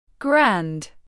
Grand /ɡrænd/